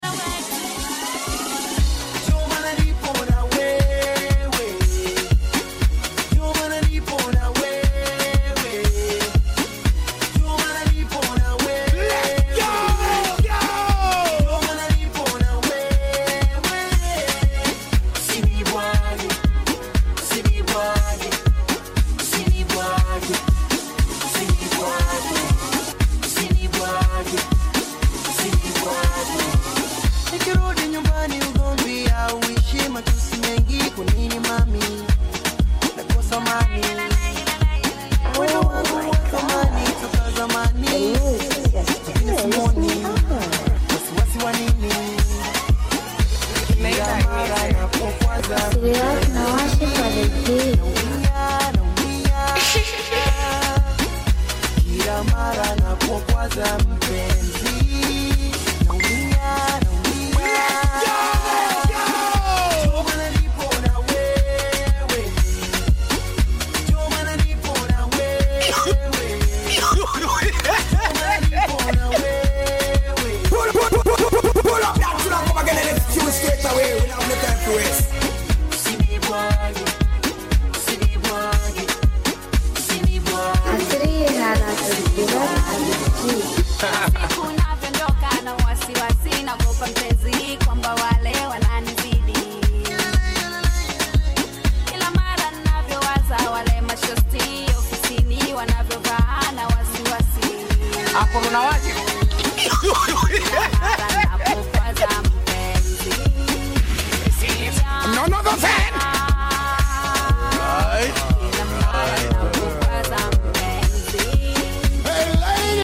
افريقيا اغاني